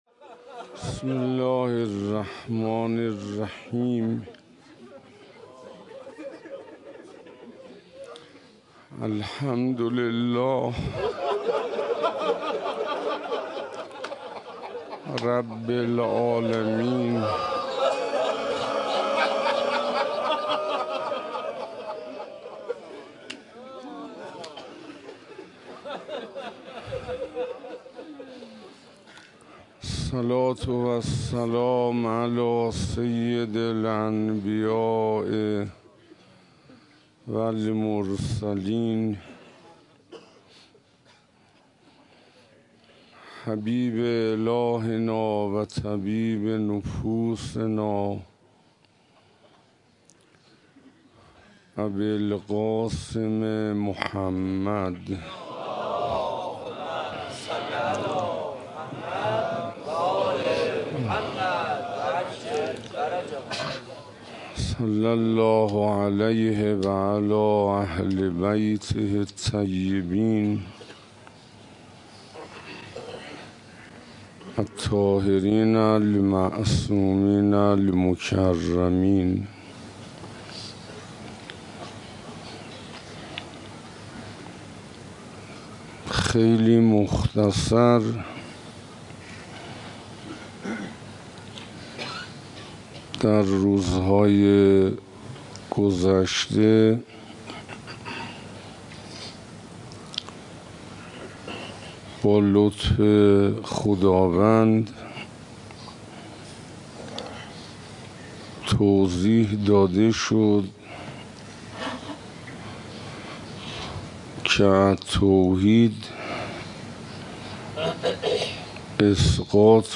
روز عاشورا محرم 97 - حسینیه علوی تهرانی - زندگی توحیدی